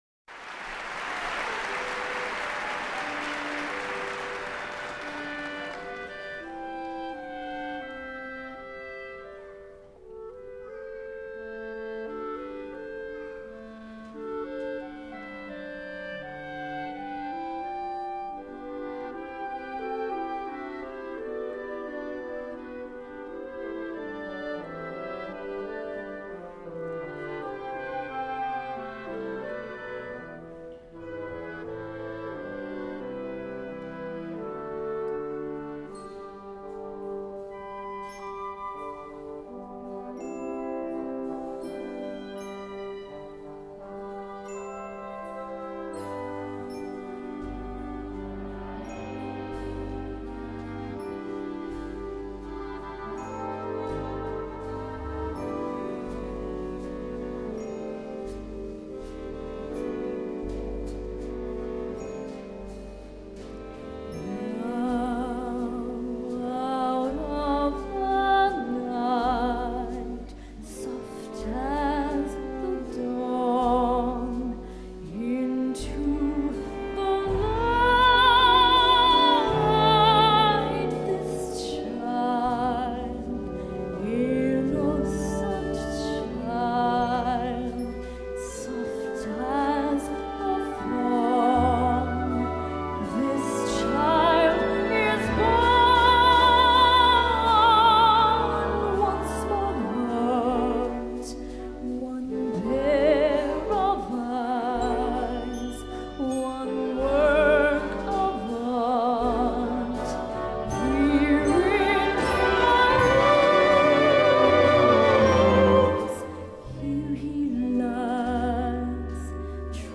Concert Band With Vocals